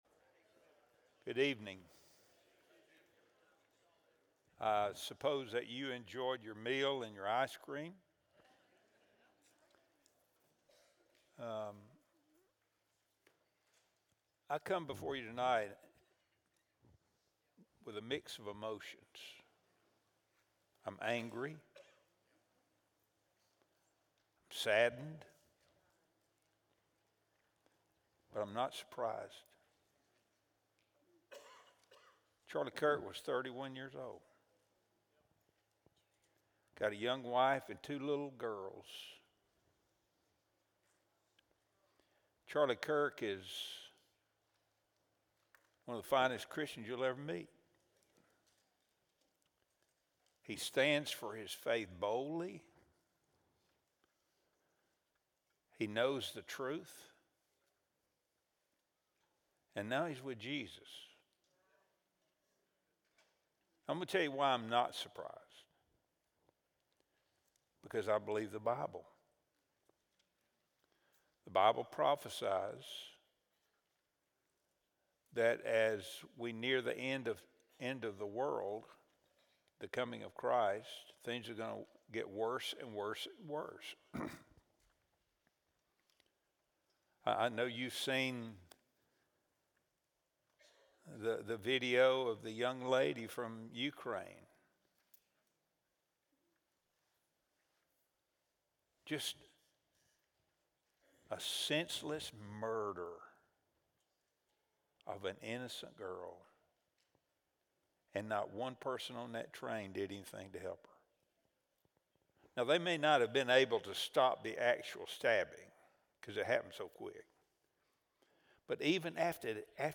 Wednesday Bible Study | September 10, 2025